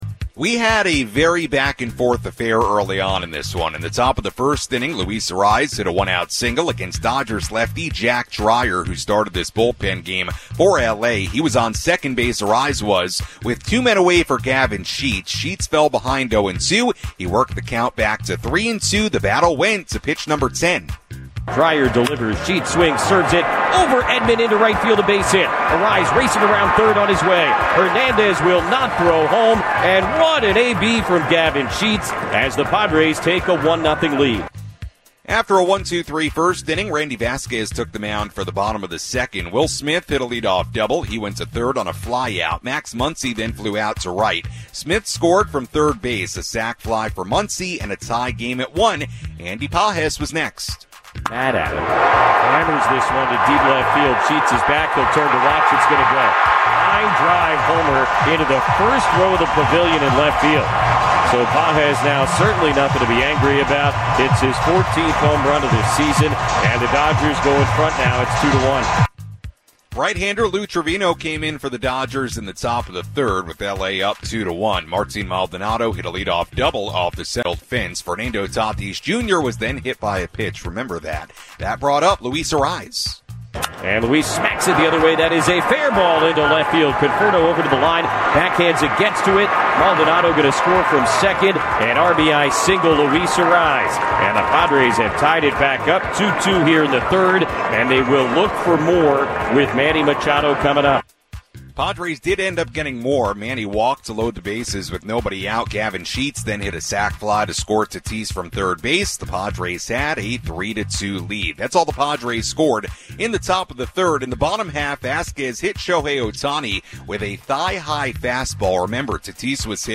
along with play-by-play radio highlights.